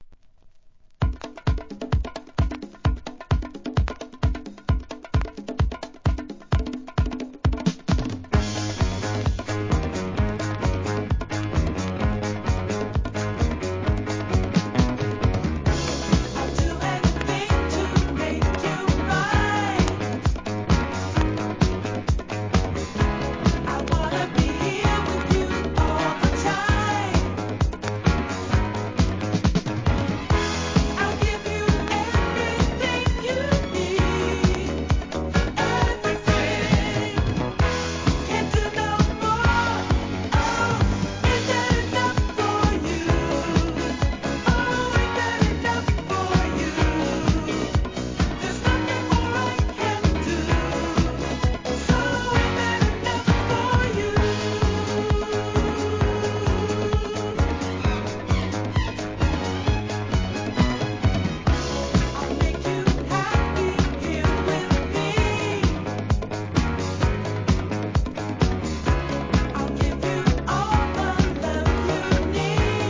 B (A面序盤にやや周期的なノイズ)
SOUL/FUNK/etc...